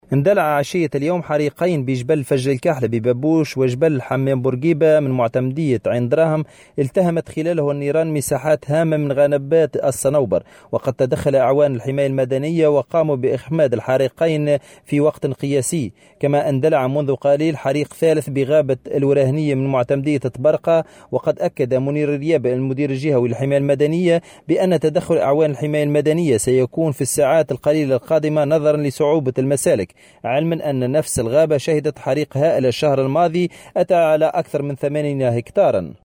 مراسلنا في الجهة